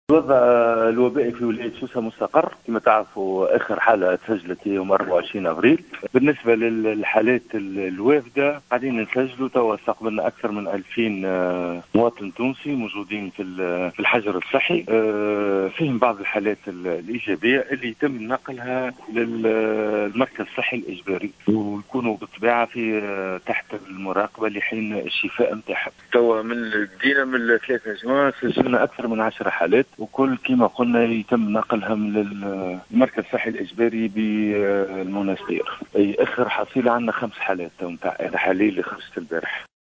أكدّ المدير الجهوي للصحة بسوسة سامي الرقيق في تصريح للجوهرة أف أم، استقبال أكثر من 2000 مواطن تونسي يتم إخضاعهم للحجر الصحي، منهم حالات إيجابية.